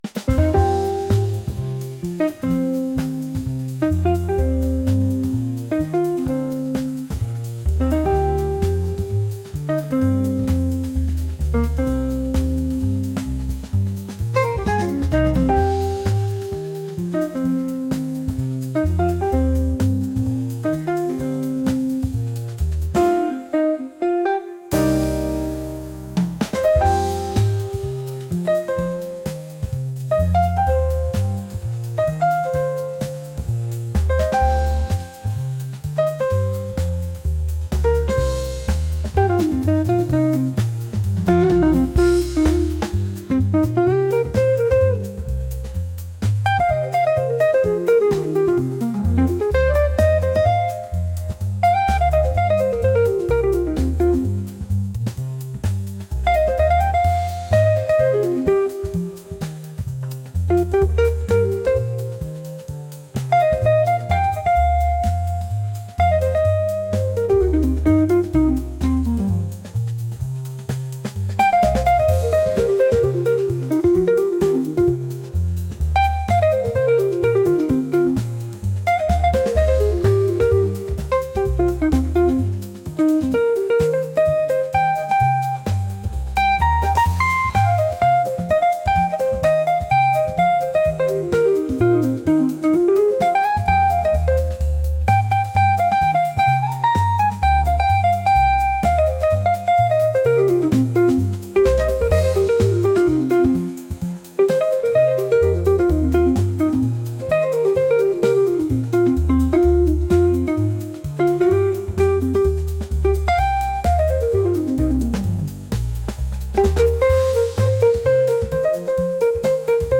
smooth | jazz